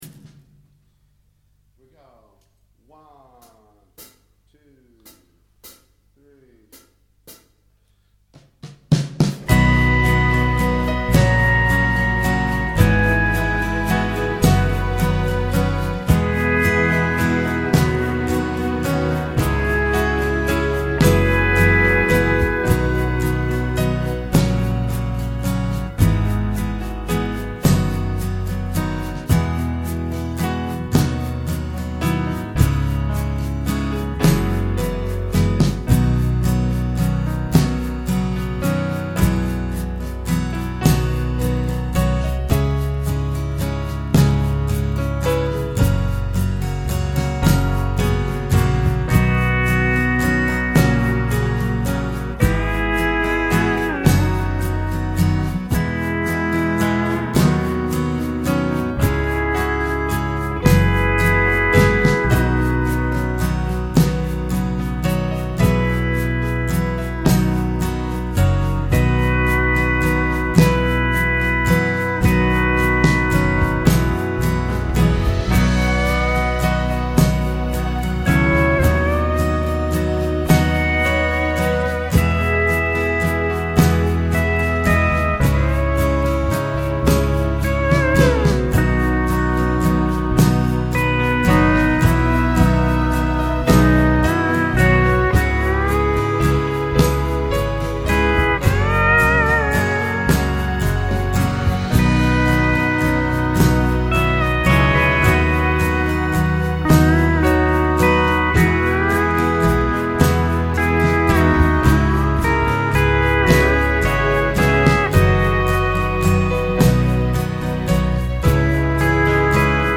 Southern Gospel Music Bed Featuring Pedal Steel Guitar
Pedal Steel
Drums
Bass Guitar
Piano and Keyboard Strings
Acoustic Guitar